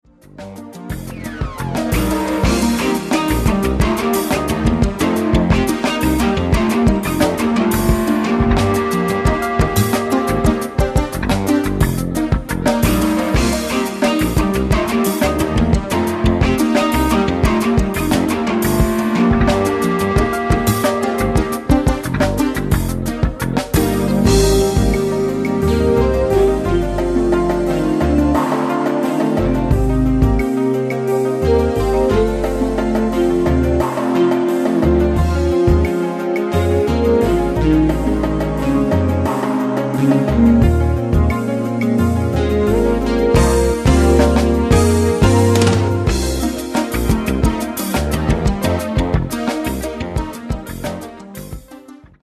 Jazz-funk.